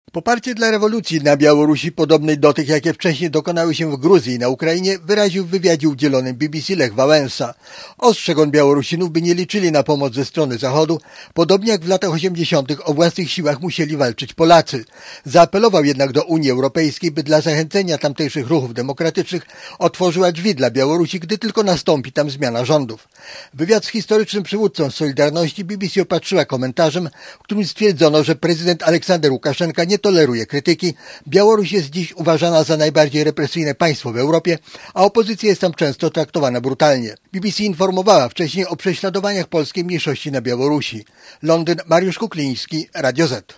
Relacja
walesa_dla_bbc.mp3